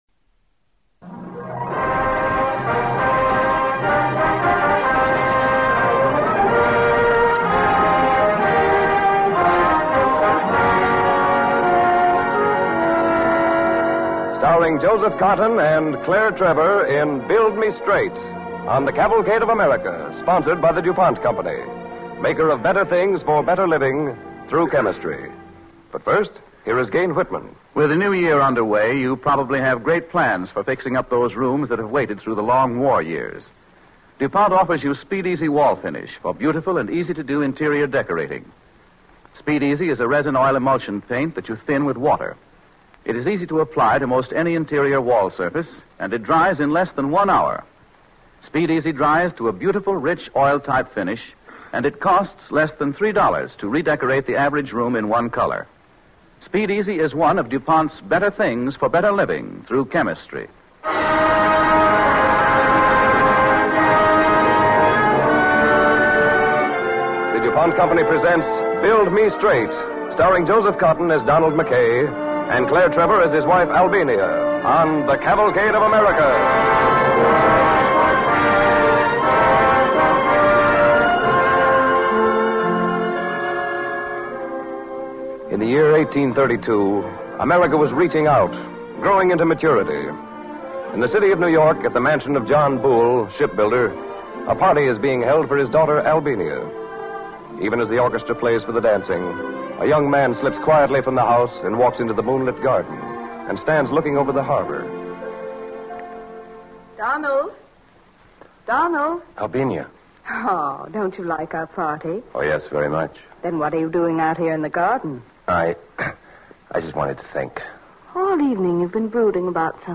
starring Joseph Cotten and Claire Trevor